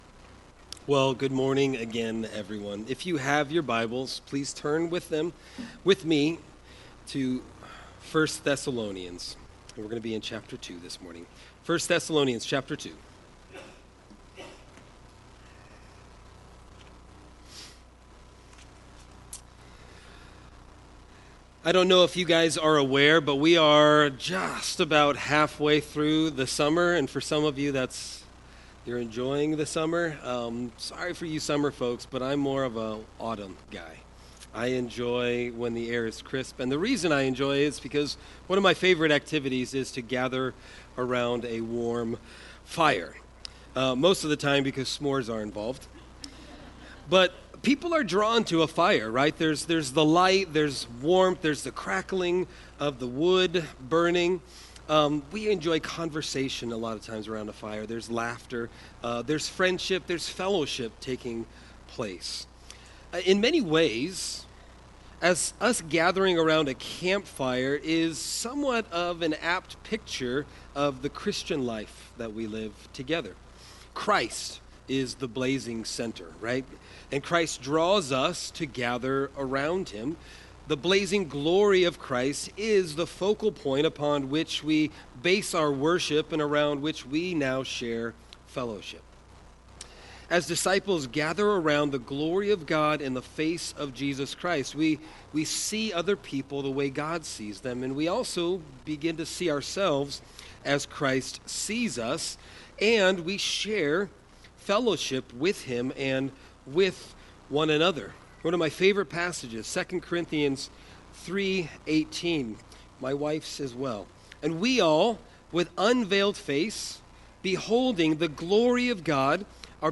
Sermon Text: 1 Thessalonians 2:1-8